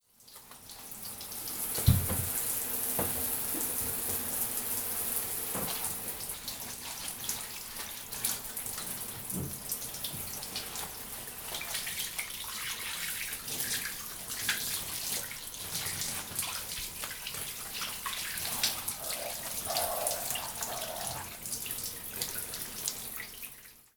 shower.wav